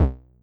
5 Harsh Realm MFB Bass Throb.wav